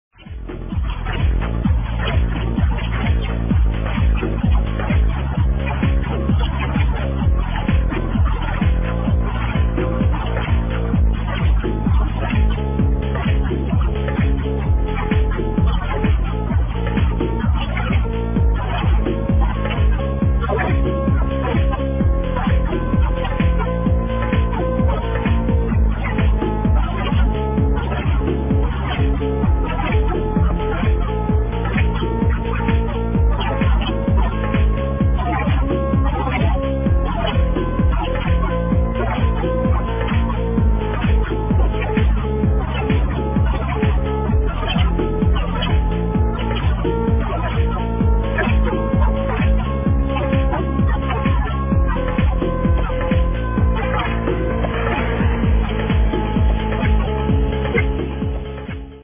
Progressive tune - please help with IDing
Please help ID this pretty dark prog tune.